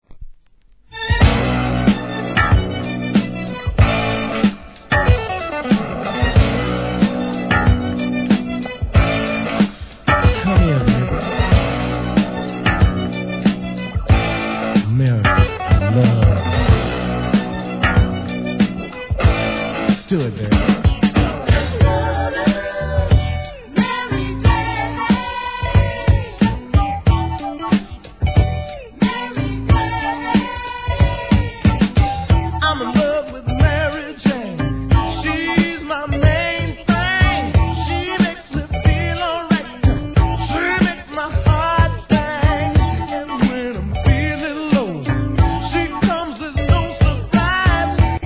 ¥ 770 税込 関連カテゴリ SOUL/FUNK/etc...